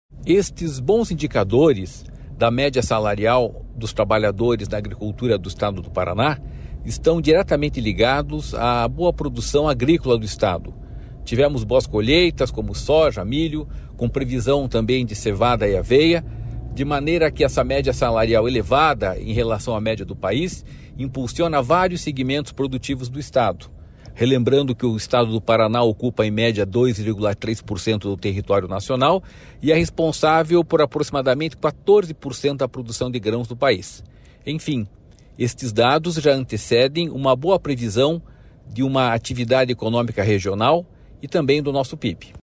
Sonora do diretor-presidente do Ipardes, Jorge Callado, sobre o crescimento do rendimento médio do trabalhador agrícola paranaense